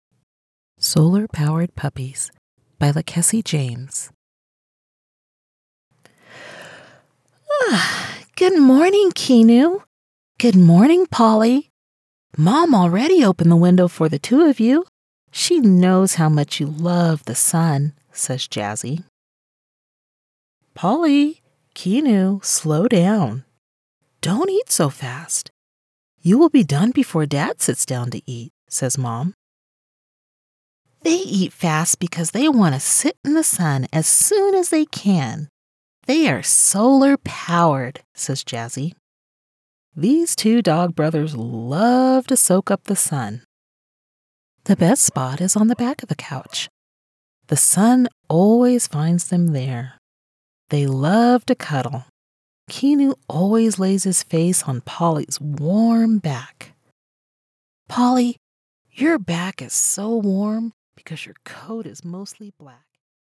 Character & Animation
Versatile vocal acting for gaming, animation, and creative projects requiring distinct personalities and creative range.
Every audition and final track is recorded in professional-grade studios for pristine clarity.